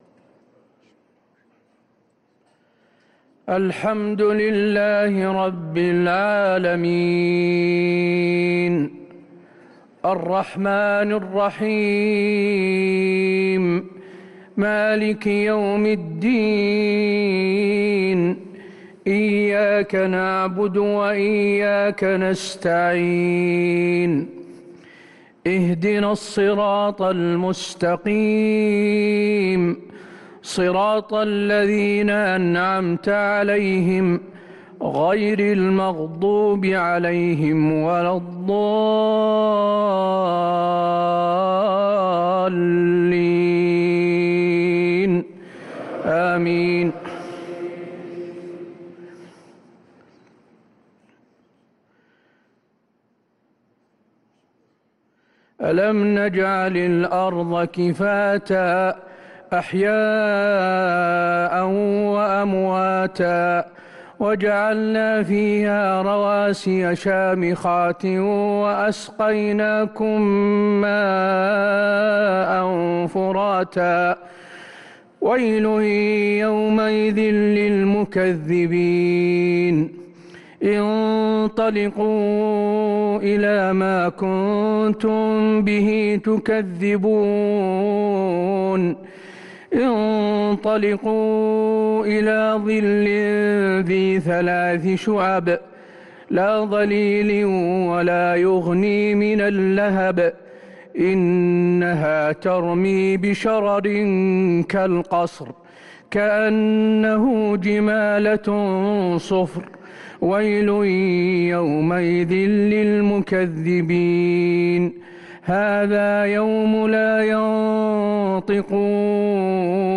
صلاة العشاء للقارئ حسين آل الشيخ 29 ذو القعدة 1444 هـ
تِلَاوَات الْحَرَمَيْن .